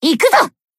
BA_V_Iori_Battle_Shout_3.ogg